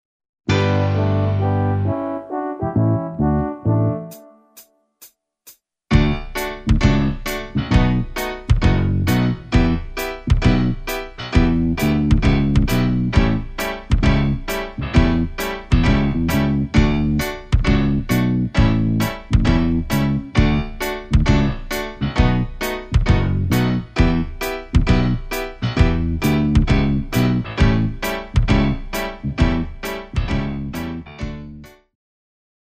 Karaoke Soundtrack
Backing Track without Vocals for your optimal performance.